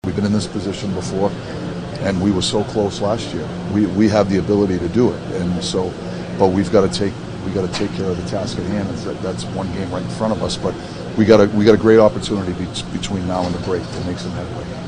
An upbeat coach Mike Sullivan said after Wednesday’s game that the Penguins are in position to take a run at the playoffs.